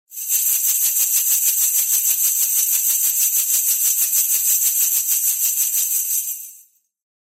Danza árabe, bailarina haciendo el movimiento twist 01
continuo
moneda
Sonidos: Acciones humanas